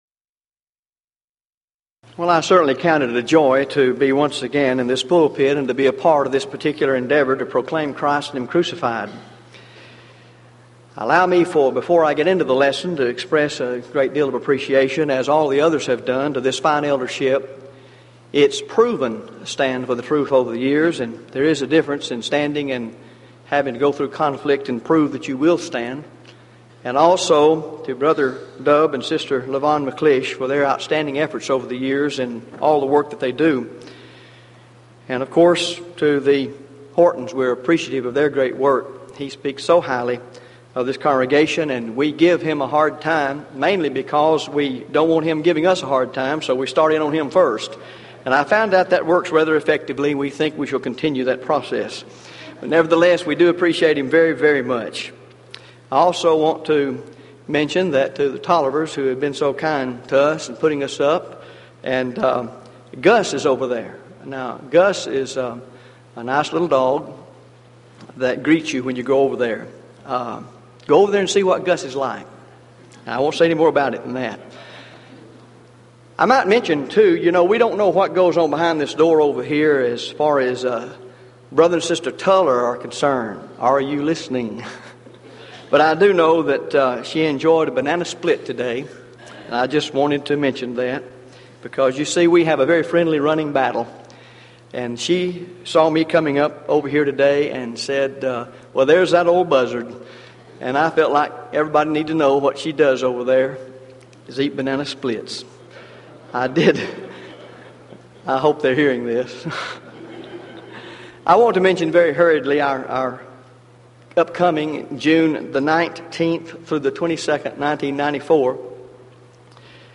Event: 1993 Denton Lectures
lecture